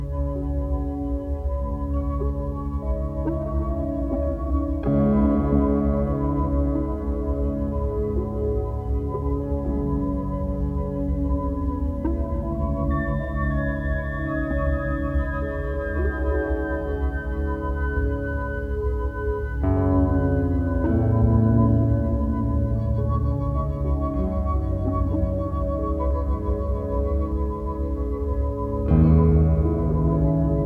Musique audio